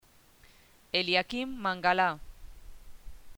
Guía de Pronunciación BRASIL 2014
Eliaquim MANGALAEliakím Mangalá